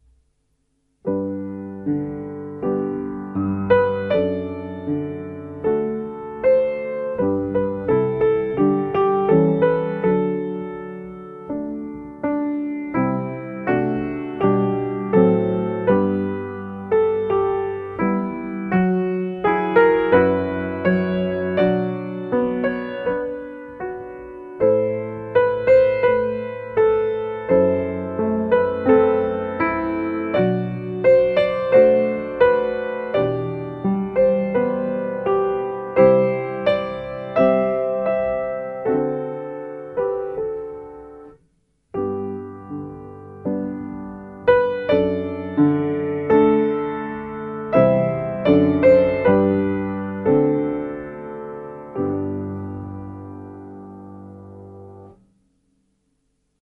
Mp3 – Esecuzione al Pianoforte (artista giapponese)
70SalveClementePiano.mp3